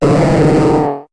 Cri de Tarpaud dans Pokémon HOME.